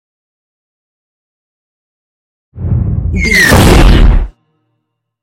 Scifi whoosh to hit
Sound Effects
dark
futuristic
intense
woosh to hit